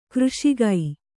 ♪ křṣigai